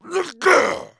client / bin / pack / Sound / sound / monster / maenghwan / attack_2.wav
attack_2.wav